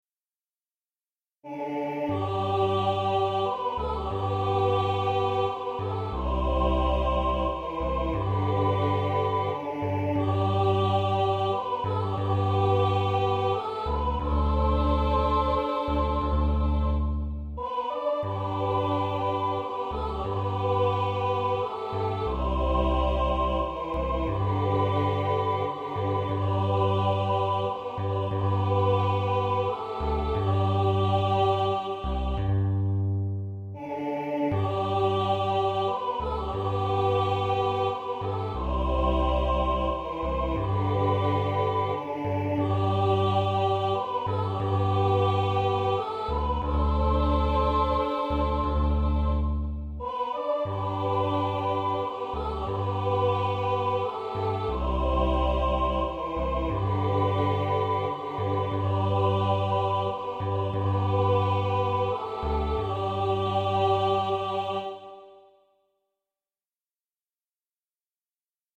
Partitions et enregistrements audio séquenceur du morceau Amazing Grace, de Traditionnel, Traditionnel.